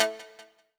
synTTE55014shortsyn-A.wav